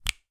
click-finger-snap.wav